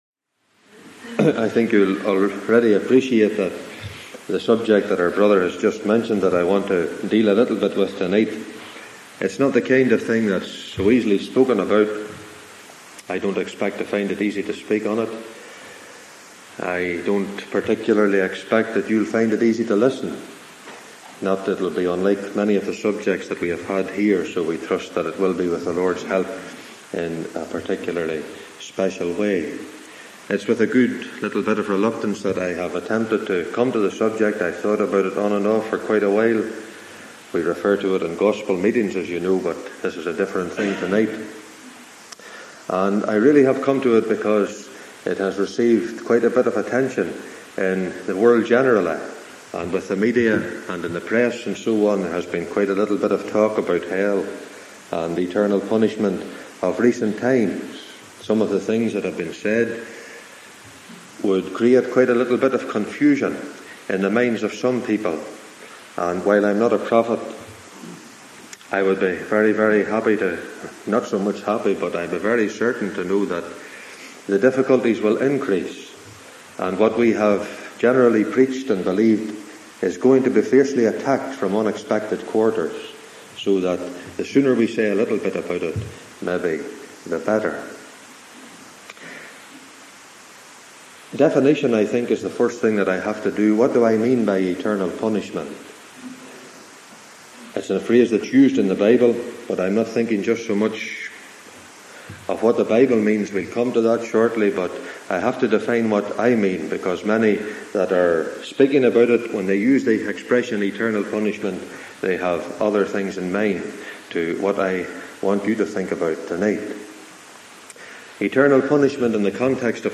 Sermons of Special Interest
This solemn and helpful message was given on 24th Feb 1996 in Ballymena.